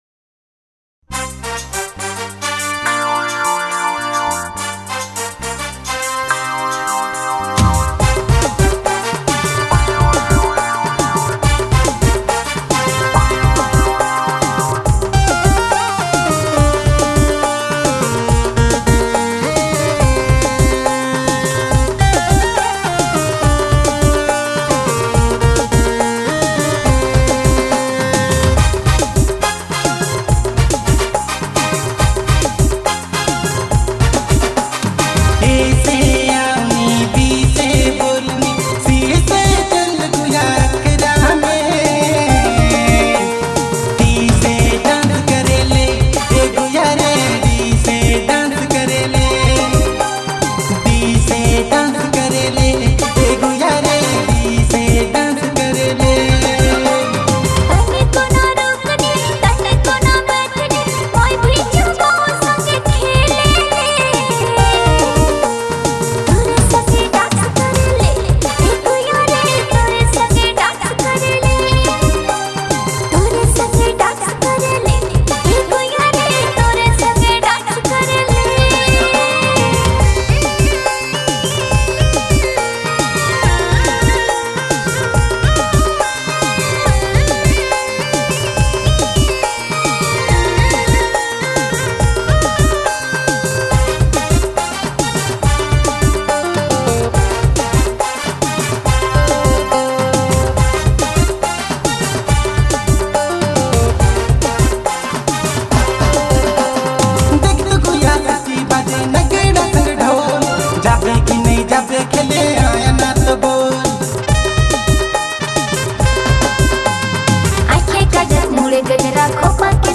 electrifying remix track
Nagpuri DJ song